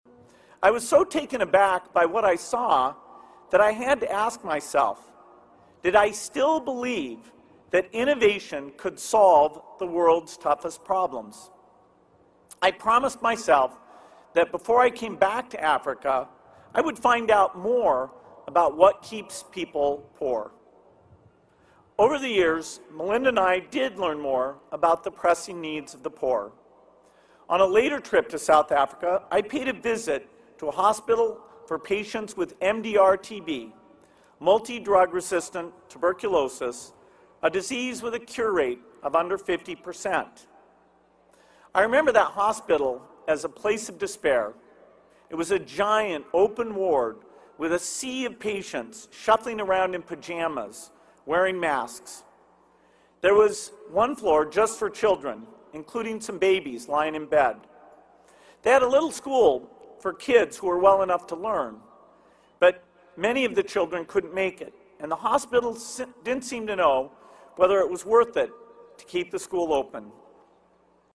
公众人物毕业演讲第27期:比尔盖茨夫妇于斯坦福大学(8) 听力文件下载—在线英语听力室